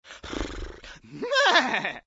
AV_horse_short.ogg